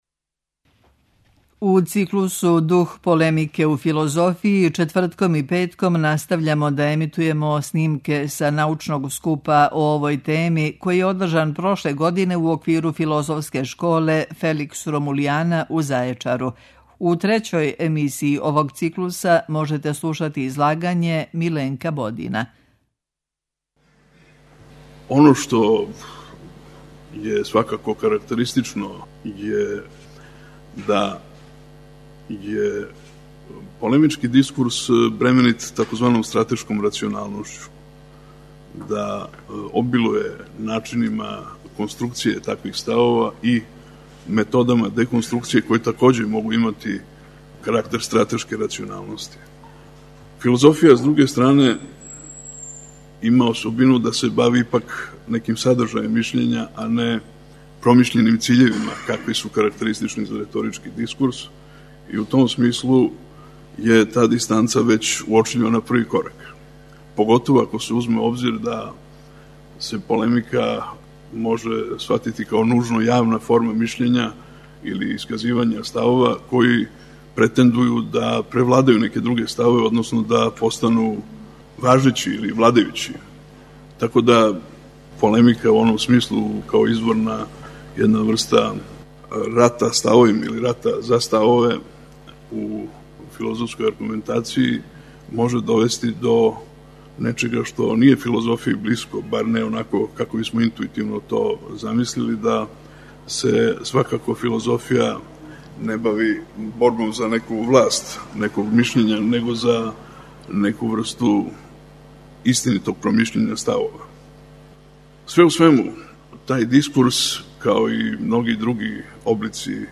У циклусу ДУХ ПОЛЕМИКЕ У ФИЛОЗОФИЈИ четвртком и петком емитујемо снимке са научног скупа о овој теми, који је одржан прошле године у оквиру Филозофске школе Феликс Ромулиана у Зајечару.
Научни скупови
Прошлог лета у Зајечару одржана је шеснаеста Филозофска школа Феликс Ромулиана, научни скуп са традицијом дугом 22 године.